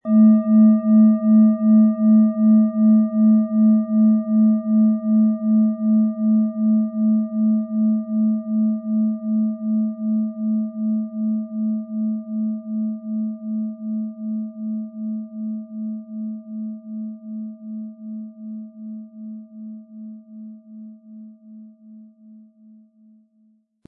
Tibetische Bauch-Herz-Fuss- und Kopf-Klangschale, Ø 18,1 cm, 800-900 Gramm, mit Klöppel
Unter dem Artikel-Bild finden Sie den Original-Klang dieser Schale im Audio-Player - Jetzt reinhören.
Den passenden Schlägel erhalten Sie kostenlos mitgeliefert, er lässt die Klangschale harmonisch und wohltuend ertönen.
MaterialBronze